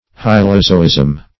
Search Result for " hylozoism" : The Collaborative International Dictionary of English v.0.48: Hylozoism \Hy`lo*zo"ism\, n. [Gr.
hylozoism.mp3